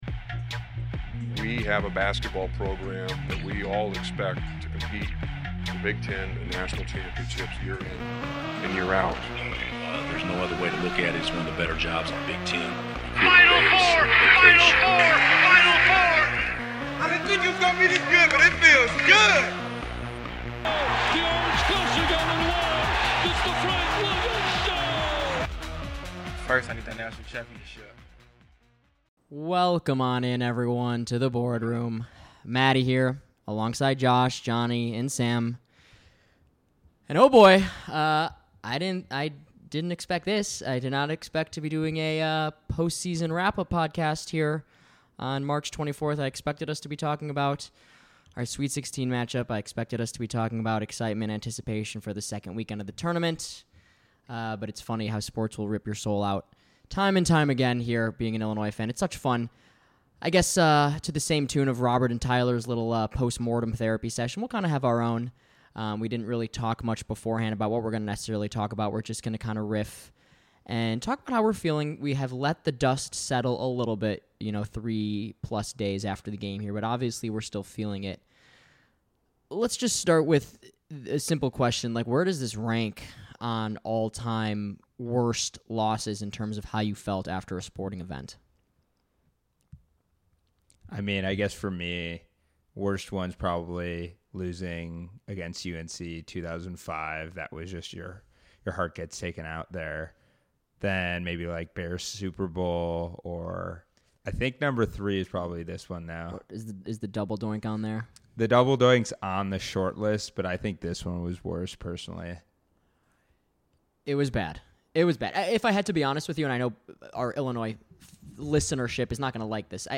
Hear the guys discuss their emotional reactions to the Illini loss to Loyola in the round of 32 and the legacy this team will leave.